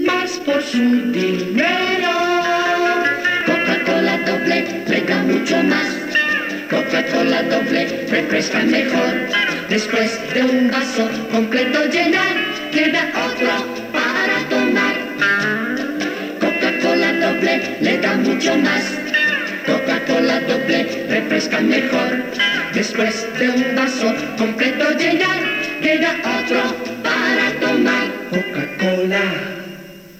Anunci de Coca Cola doble
L'enregistrament de l'anunci es va fer als Estudios Celada de Madrid.